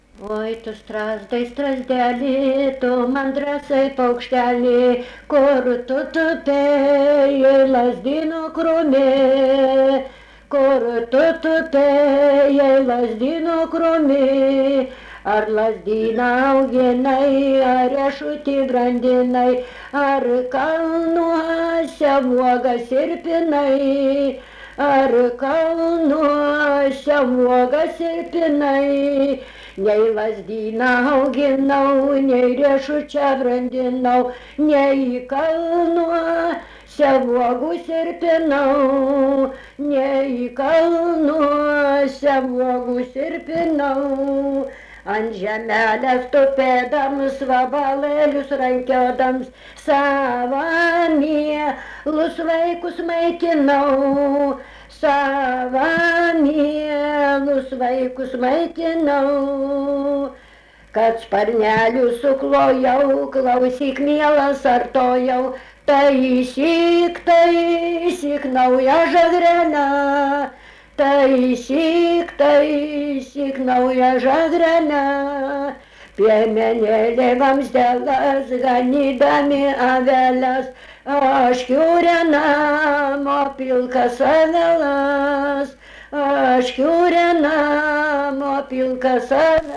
Dalykas, tema daina
Erdvinė aprėptis Laibgaliai
Atlikimo pubūdis vokalinis